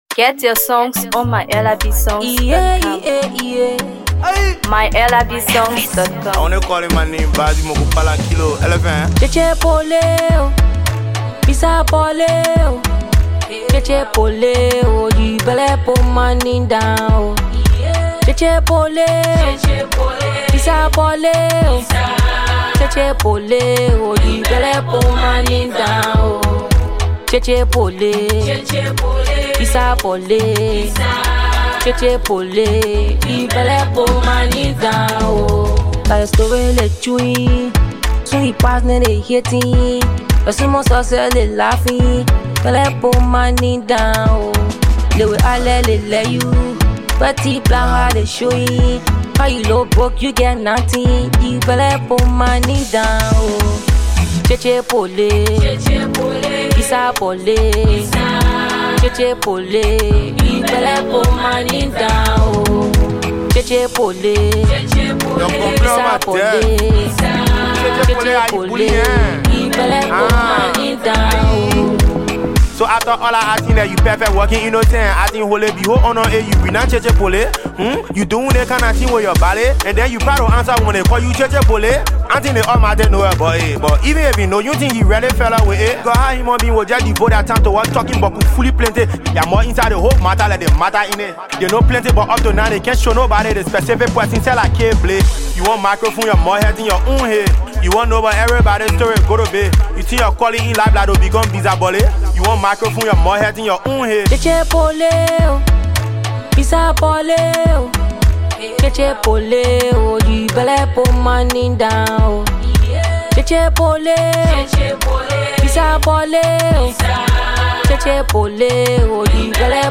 Afro PopMusic
a fusion of Afrobeat rhythms and Hipco flair